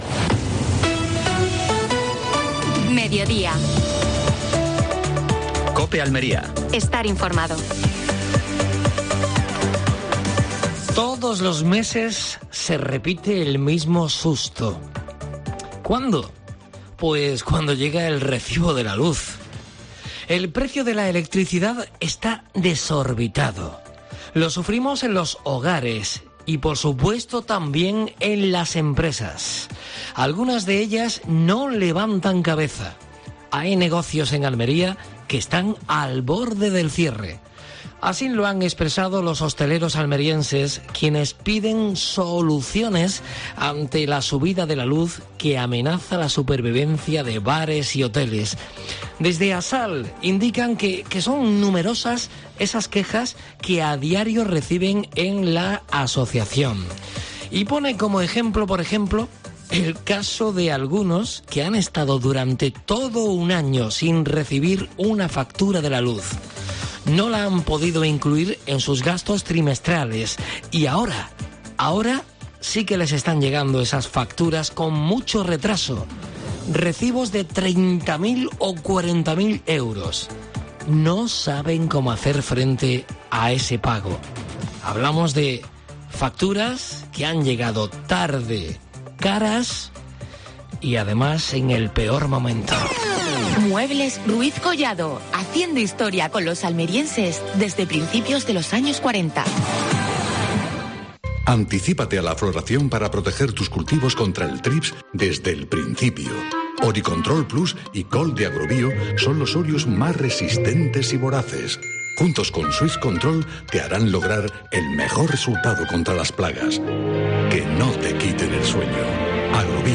Última hora deportiva.